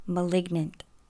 The precise opposite of "benign" is " malignant ," pronounced " muh LIG nunt ."
malignant.mp3